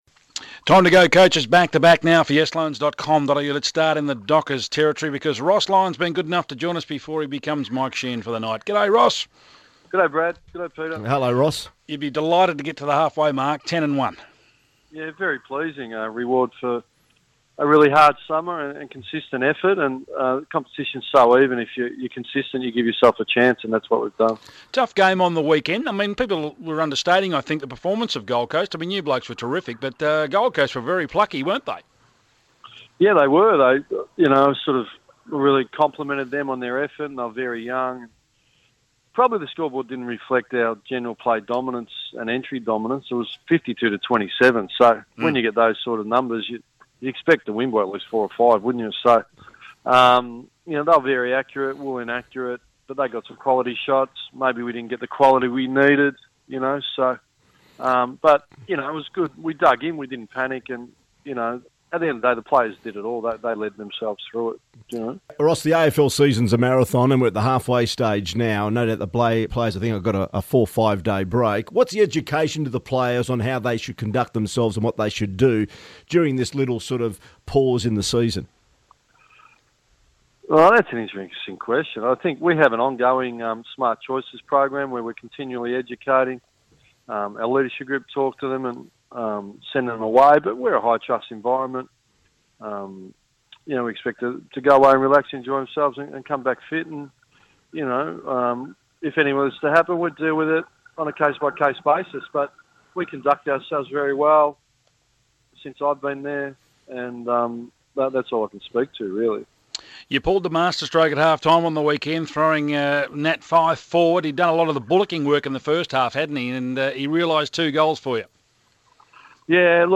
Ross Lyon speaks to Sports Today after their win over the Gold Coast